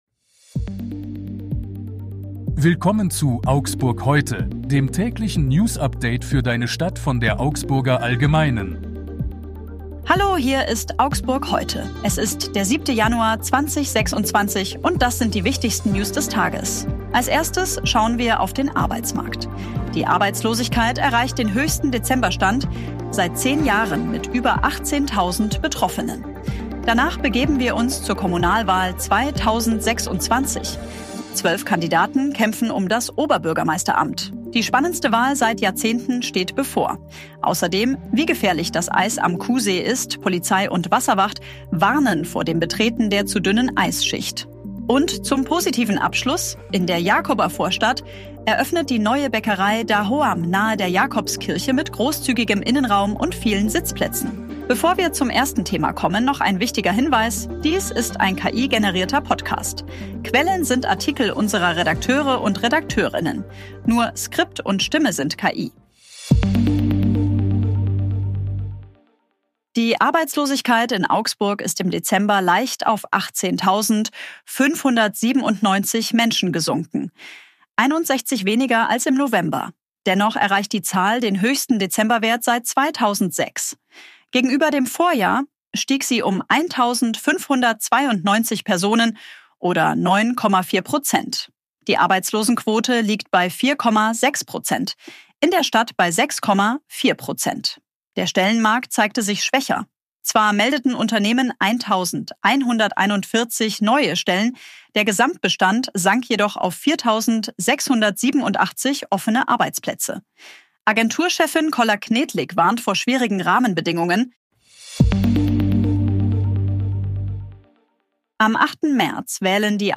Dies ist ein KI-generierter Podcast.
Nur Skript und Stimme sind KI.